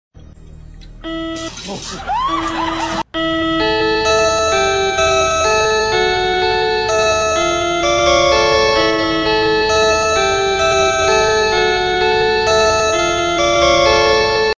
a familar sound on a game show